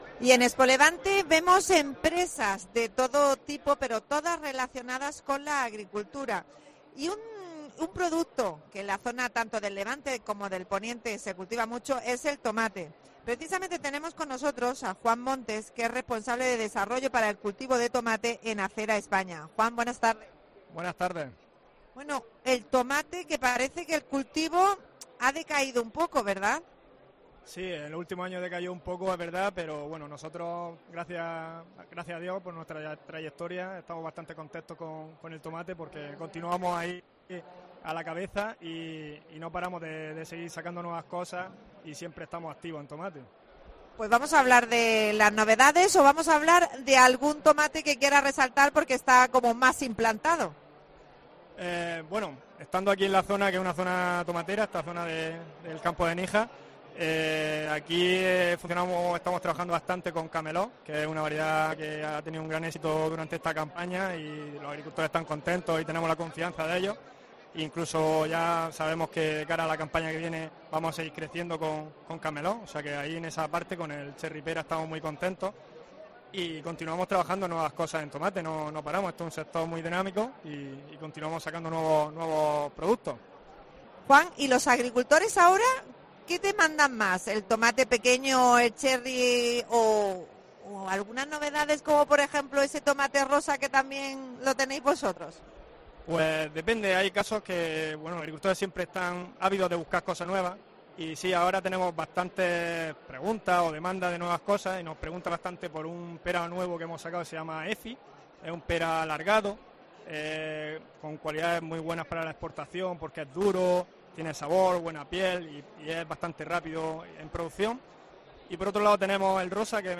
Especial ExpoLevante.